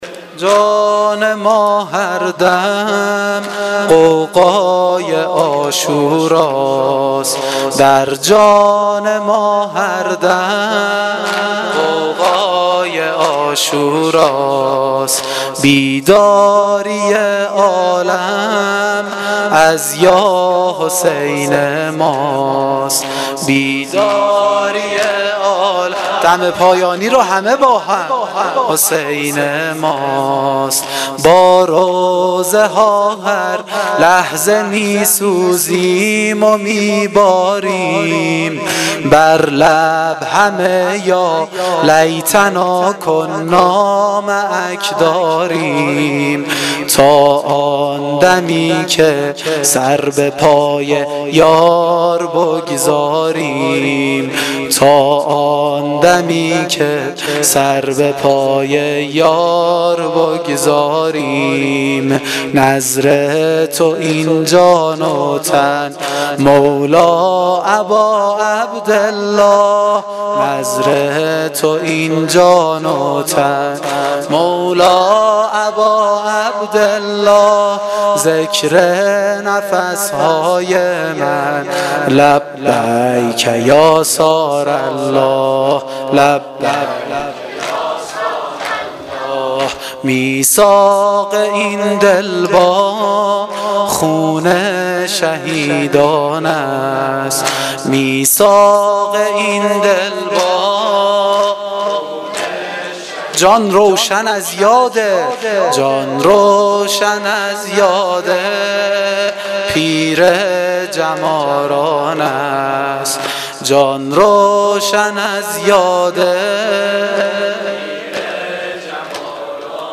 دم پایانی شب پنجم